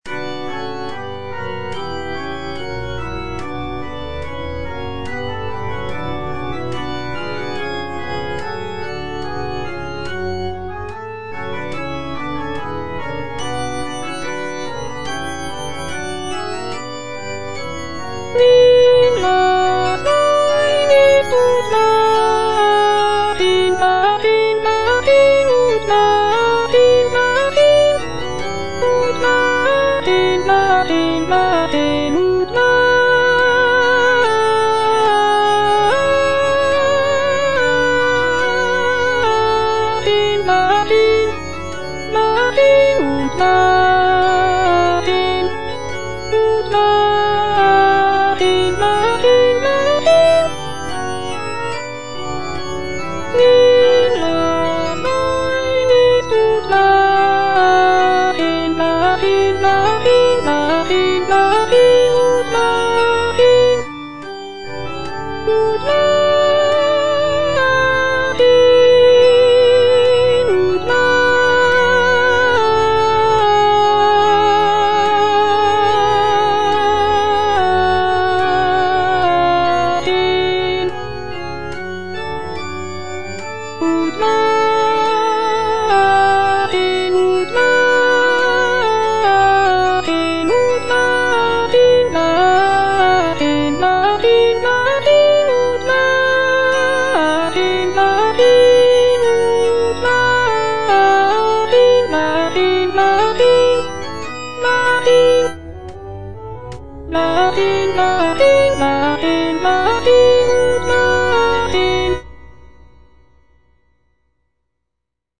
Choralplayer playing Cantata